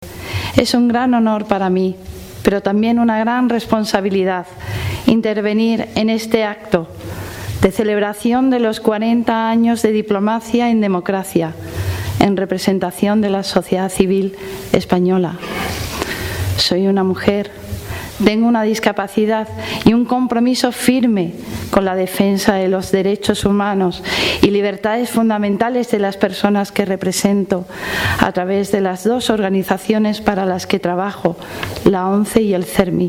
dijo Peláez formato MP3 audio(0,63 MB) en el acto, en presencia del rey, varios ministros del Gobierno, embajadores, ex ministros de Asuntos Exteriores y expresidentes del Gobierno, entre otras personalidades.